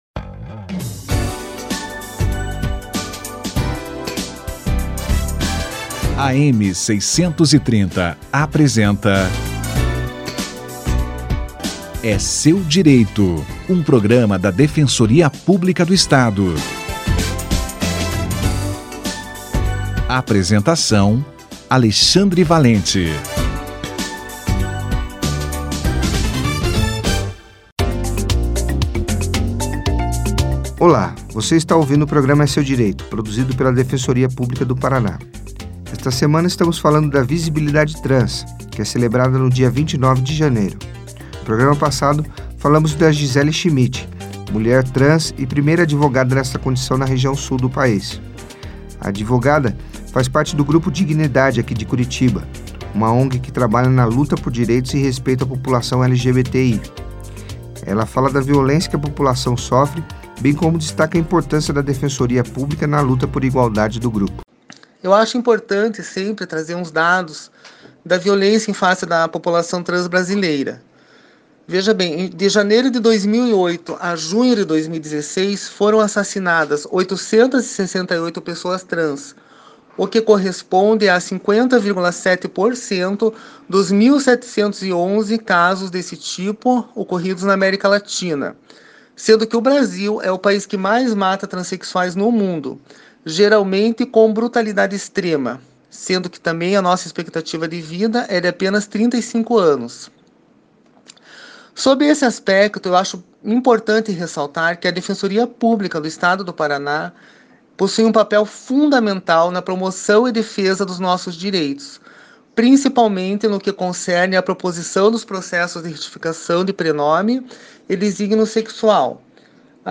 Violência contra a população trans - entrevista